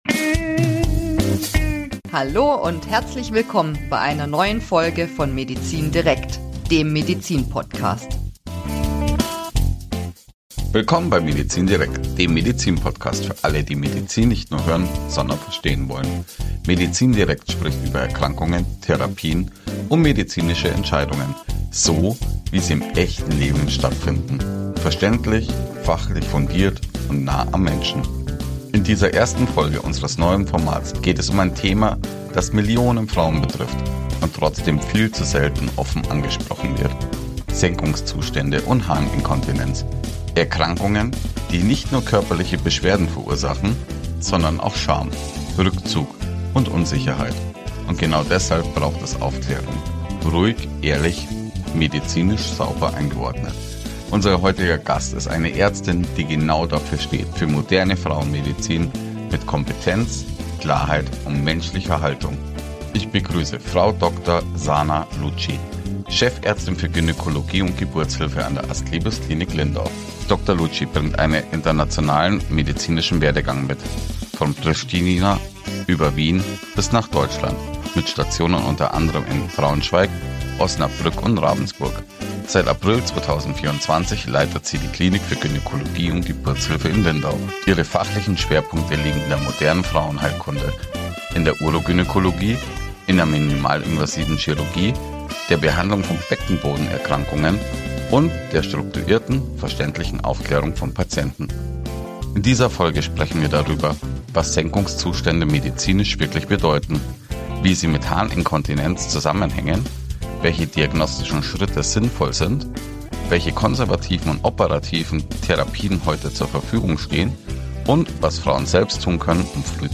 Beschreibung vor 3 Monaten Senkungszustände und Harninkontinenz betreffen Millionen Frauen – und werden dennoch häufig verschwiegen. In dieser Folge von MEDIZIN DIREKT – Der Medizin-Podcast sprechen wir offen, fachlich fundiert und verständlich über Ursachen, Diagnostik und moderne Behandlungswege.